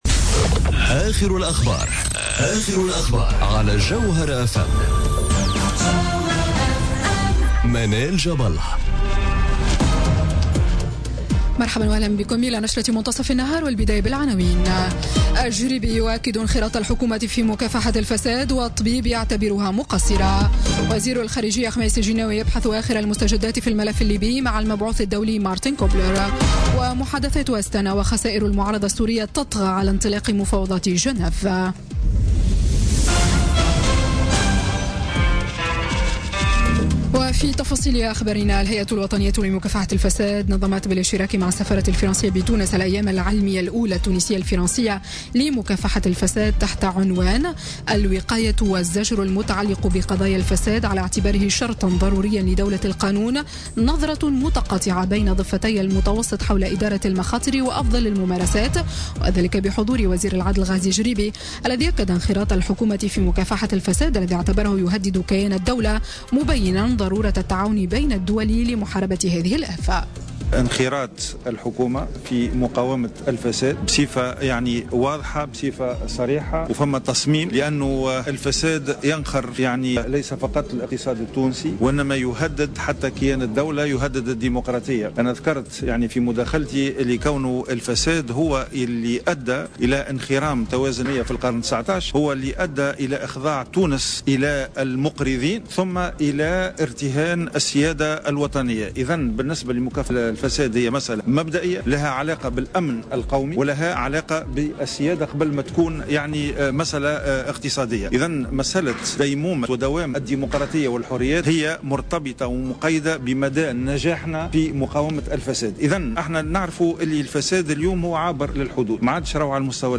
نشرة أخبار منتصف النهار ليوم الإثنين 15 ماي 2017